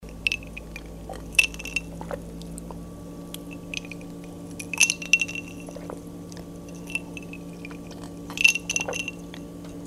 Drinking A Glass Of Water With Ice